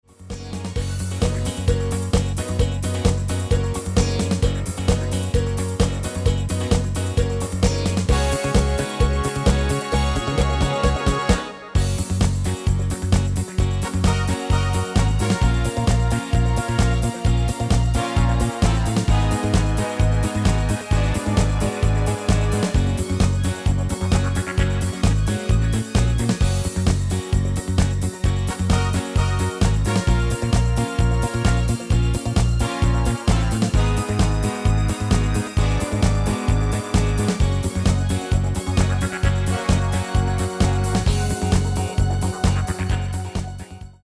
Demo/Koop midifile
Genre: Disco
Toonsoort: C#
- Vocal harmony tracks